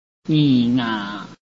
臺灣客語拼音學習網-客語聽讀拼-海陸腔-單韻母
拼音查詢：【海陸腔】nga ~請點選不同聲調拼音聽聽看!(例字漢字部分屬參考性質)